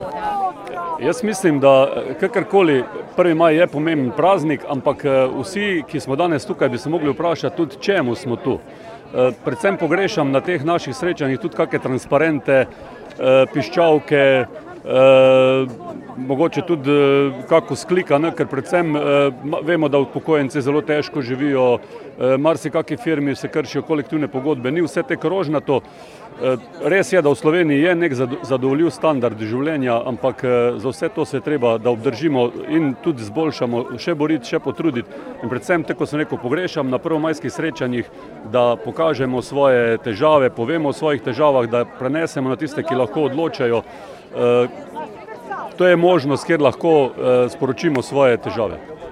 Veliko tradicionalno prvomajsko srečanje je potekalo tudi na tromeji občin Slovenj Gradec, Mislinja in Graška gora.